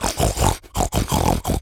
pig_sniff_deep_01.wav